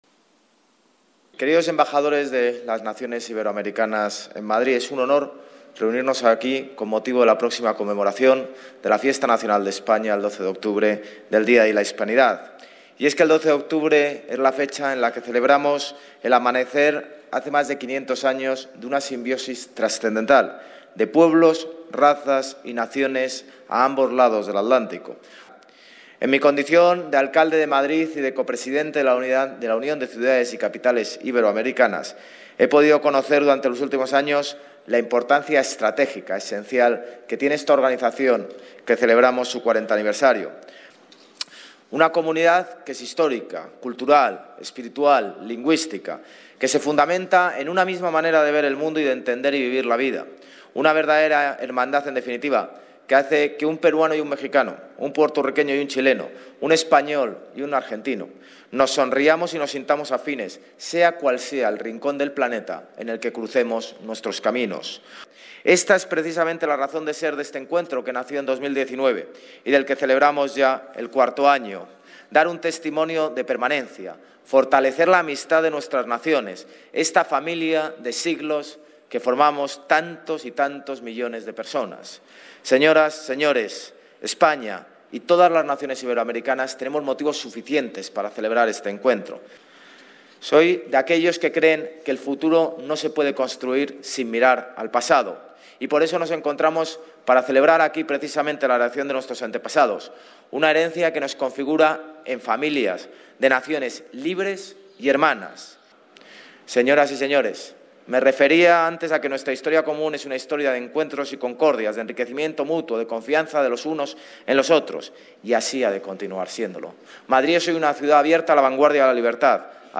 En el acto organizado por el Ayuntamiento de Madrid y la Unión de Ciudades Capitales Iberoamericanas con motivo de la Fiesta Nacional
Nueva ventana:José Luis Martínez-Almeida, alcalde de Madrid